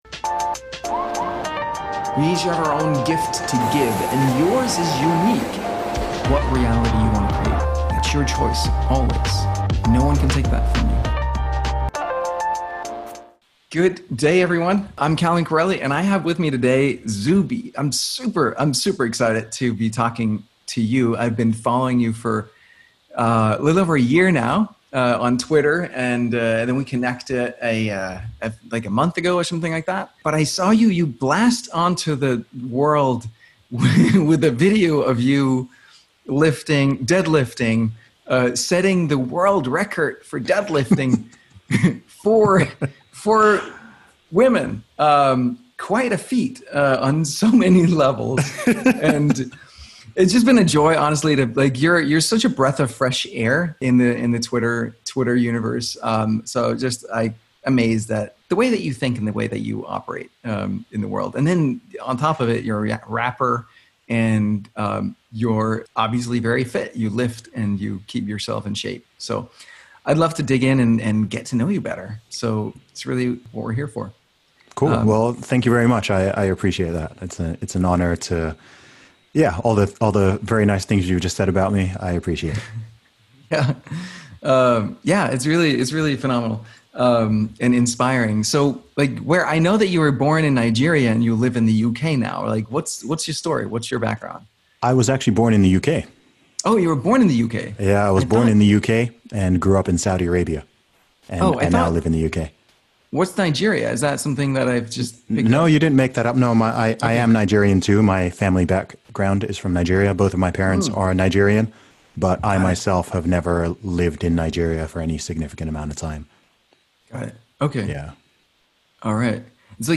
Broadening Your World View And Monetizing Your Haters Interview With Zuby